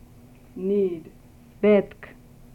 These sound files were collected from a larger group of files located Archive Databases from the Phonetics Resources at UCLA.
Sound File #3 - Below is an audio file and visual of the word, "Need" as spoken in Western Armenian. The vowel located in the transcription is ɛ. Th full transcription of the word is bɛtʰkʰ.